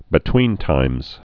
(bĭ-twēntīmz)